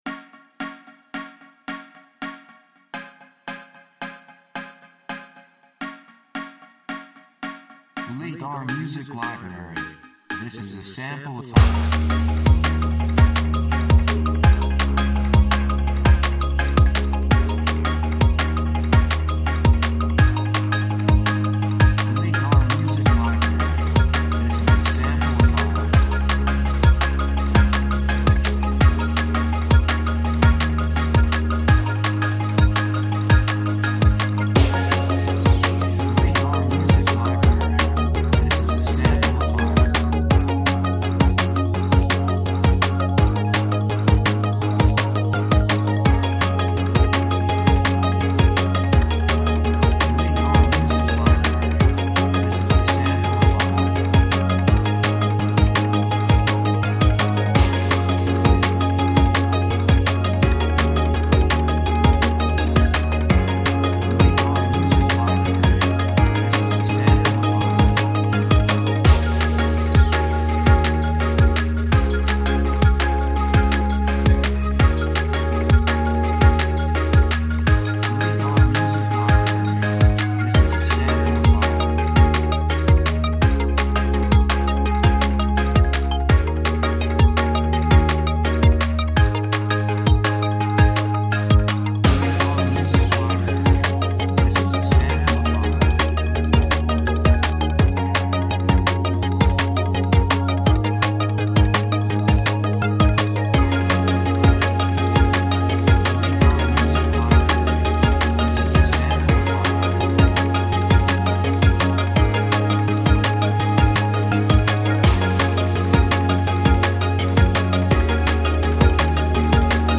雰囲気エネルギッシュ, 幸せ, せわしない, 高揚感, 決意, 夢のような, 喜び
曲調ポジティブ
楽器シンセサイザー
サブジャンルドラムンベース
テンポとても速い